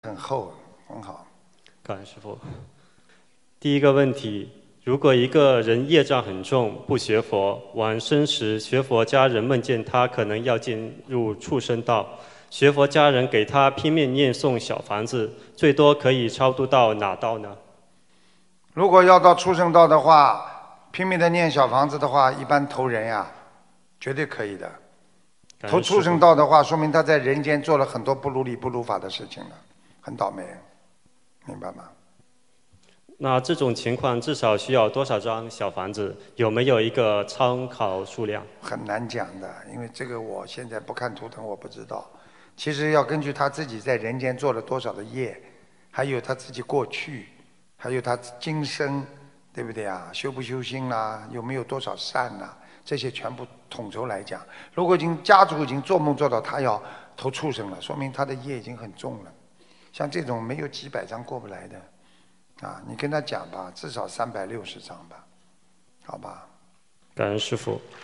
Pertanyaan di Seminar Dharma Auckland – Selandia Baru, 09 November 2019